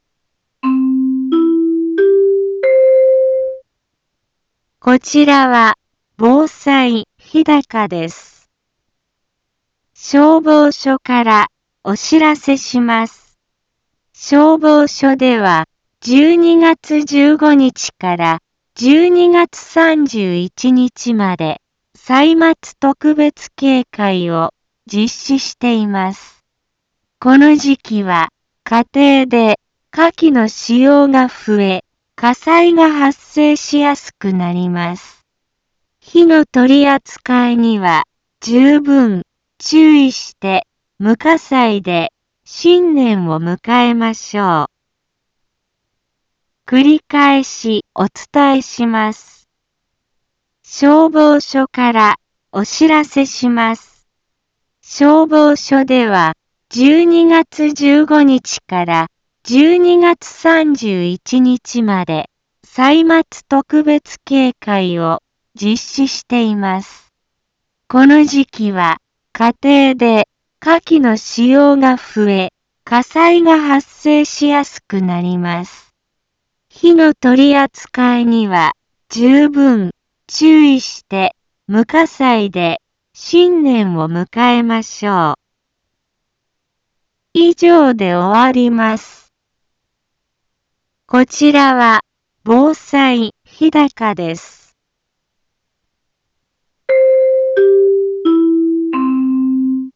Back Home 一般放送情報 音声放送 再生 一般放送情報 登録日時：2022-12-15 15:03:31 タイトル：歳末特別警戒について インフォメーション：こちらは防災日高です。